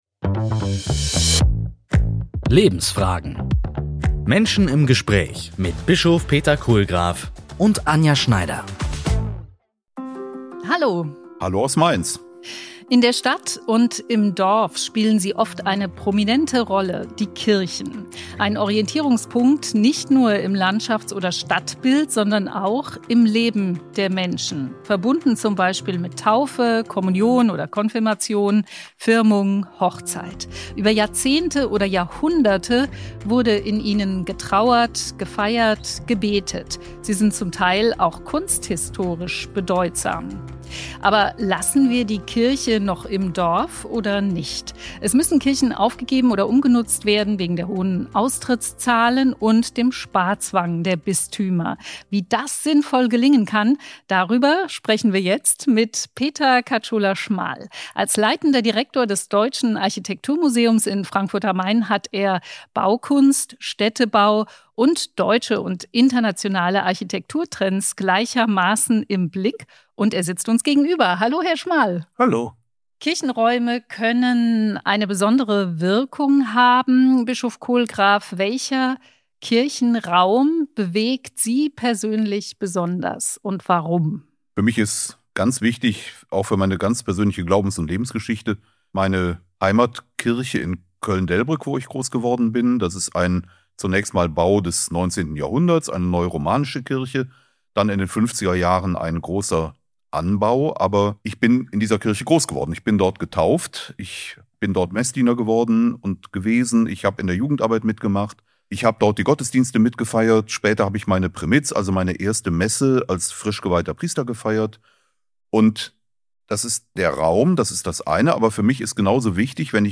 Lebensfragen - Menschen im Gespräch